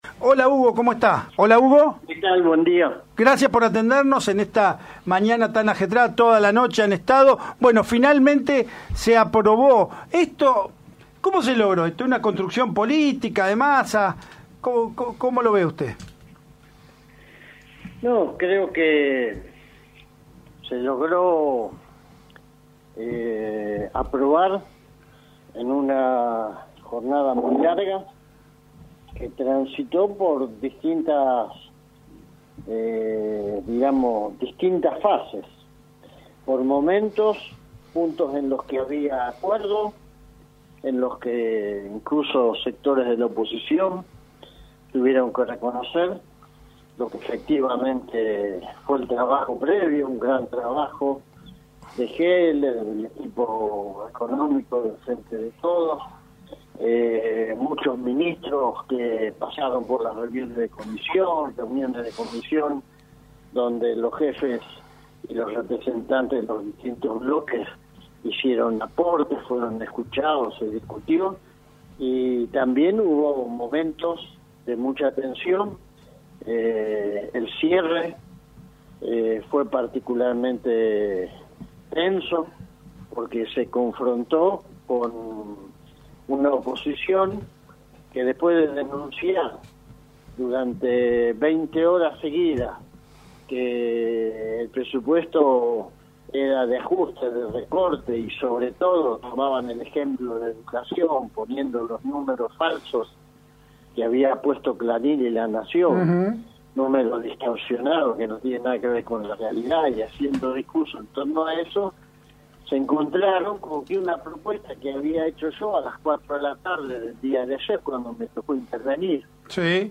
El diputado nacional del Frente de Todos, Hugo Yasky, habló sobre la votación del Presupuesto 2023 en la cámara baja en el programa RPM que conduce Rolando Graña.